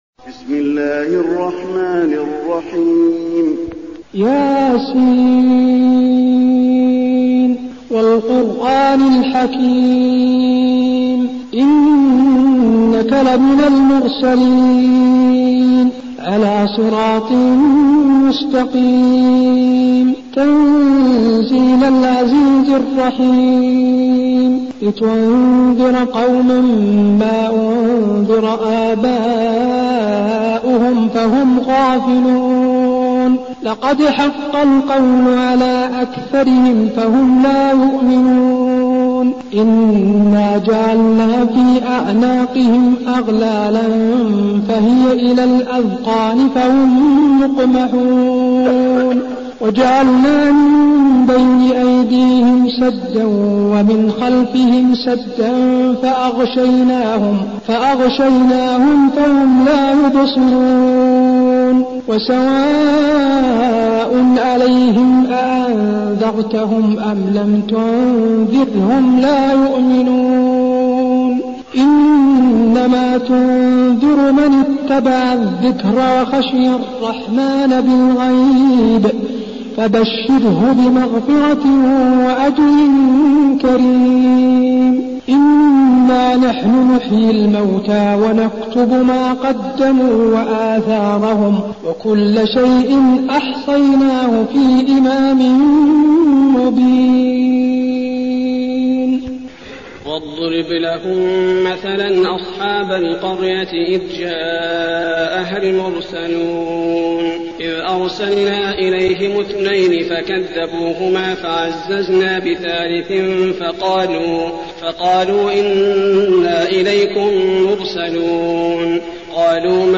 المكان: المسجد النبوي يس